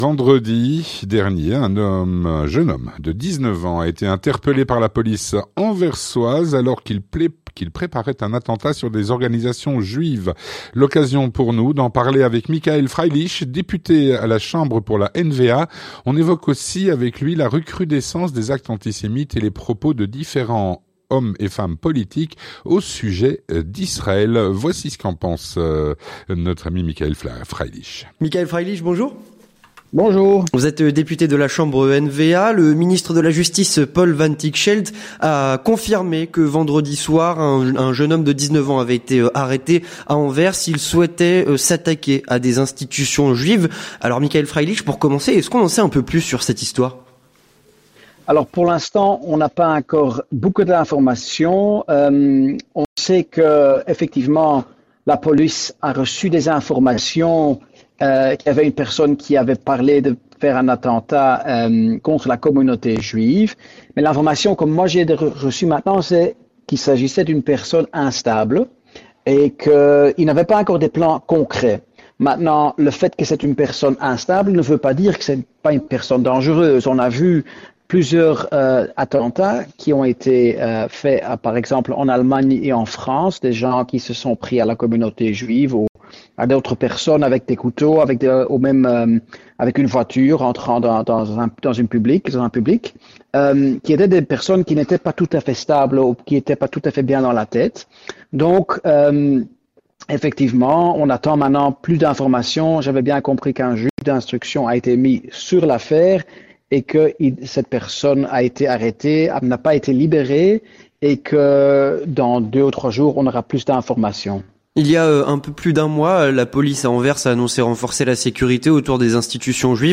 Avec Michaël Freilich, député NV-A à La Chambre